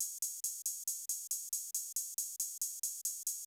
Index of /90_sSampleCDs/Best Service ProSamples vol.54 - Techno 138 BPM [AKAI] 1CD/Partition C/UK PROGRESSI
16TH HH   -L.wav